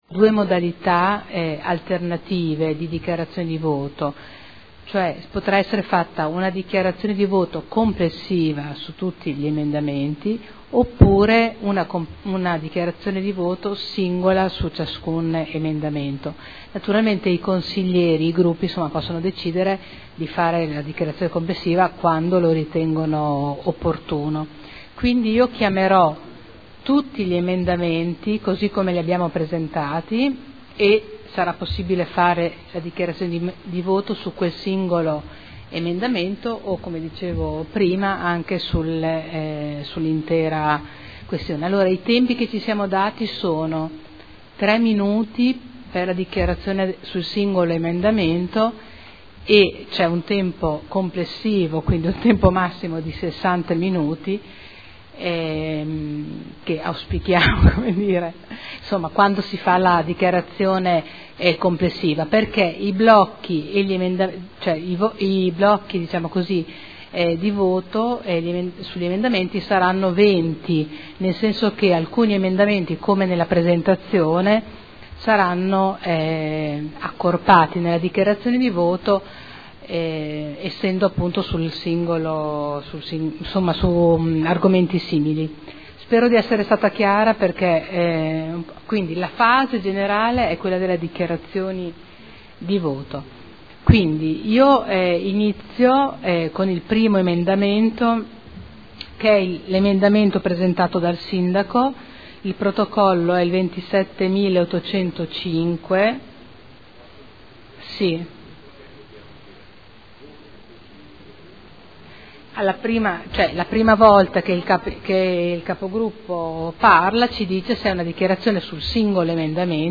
Seduta del 13 marzo. Precisazione del Presidente sulle dichiarazioni di voto